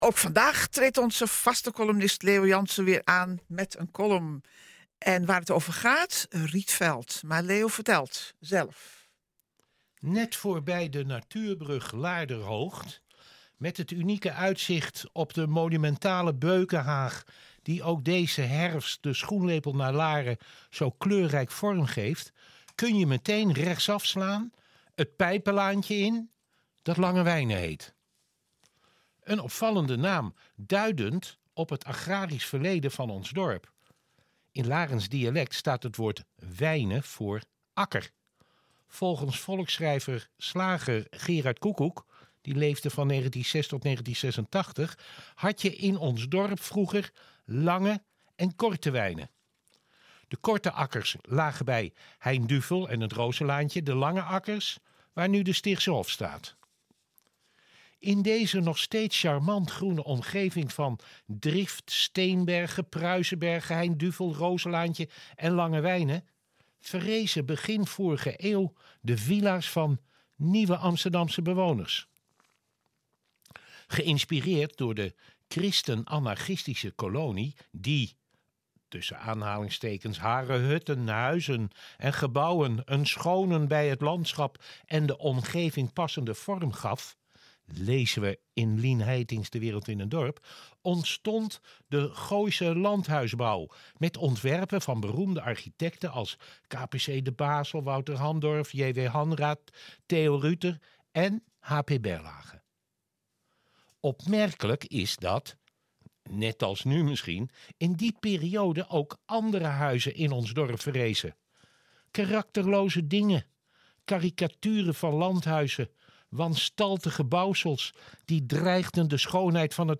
column. Dit keer over de rietveldvilla.